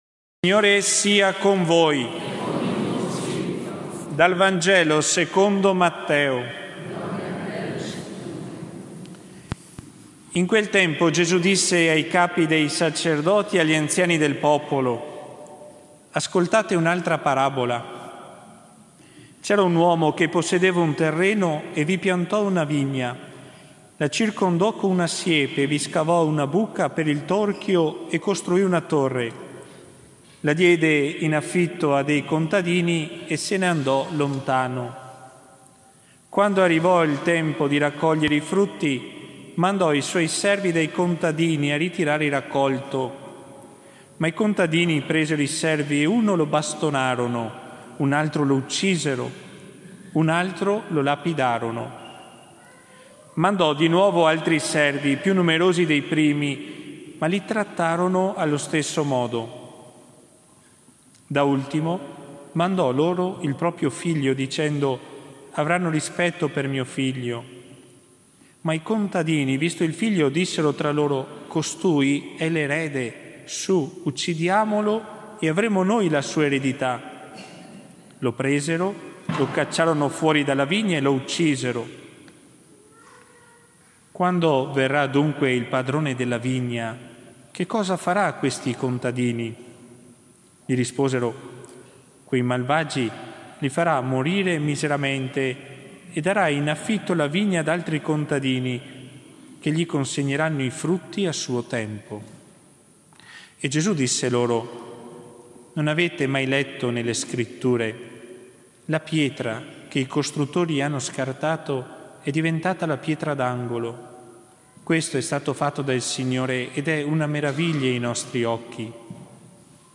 4 ottobre 2020 XXVII DOMENICA del tempo ordinario (anno A) – omelia